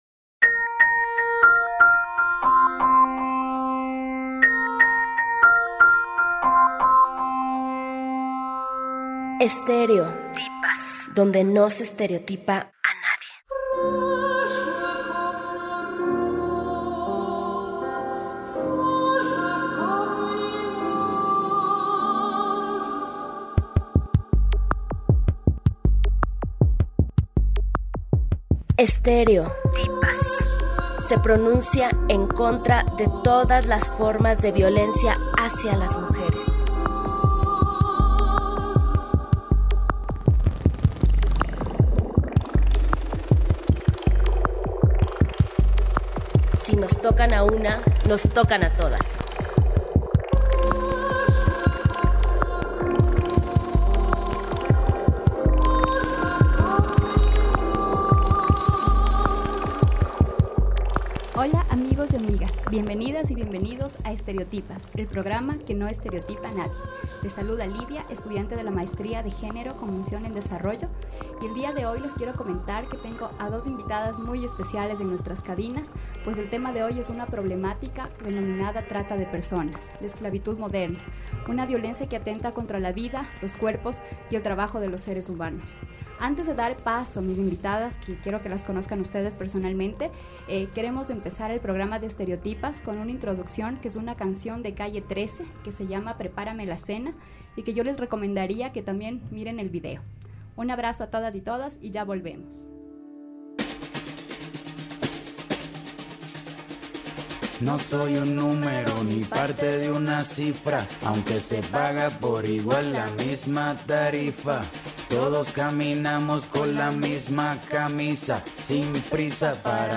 Ambas expertas en el tema indicaron que la trata de personas es una violación a los derechos humanos, pues es la compra, venta y uso de personas independientemente de su lugar de origen, edad, género y condición socioeconómica. Es decir, es la explotación de seres humanos para distintos fines: sexuales, laborales, mendicidad, tráfico de órganos, entre otros.